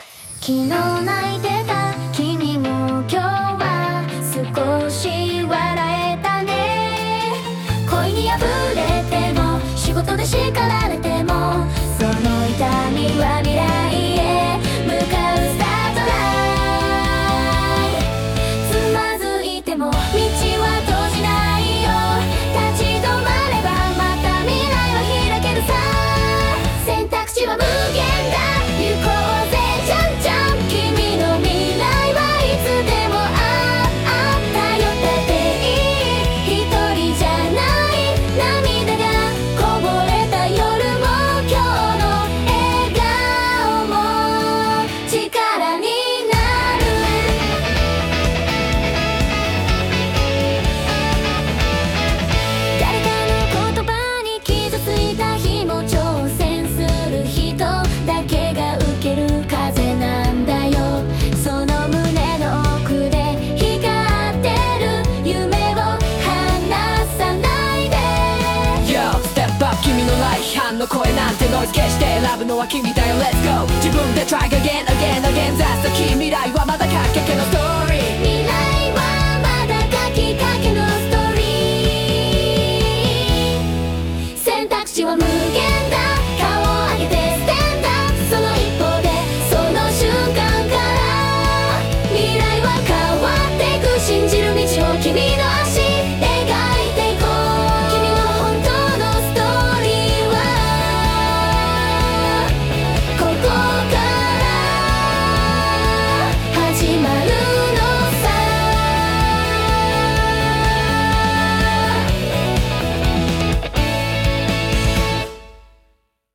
最近、元気のない若い人向けに自分で書いた歌詞を並べ替え、AIに曲として仕上げてもらったところ、まるで本物の歌手が歌っているようなクオリティで返ってきて驚愕してしまいました。ブレス音（息づかい）までリアルで、初めて聴いたときは思わず鳥肌が立ちました。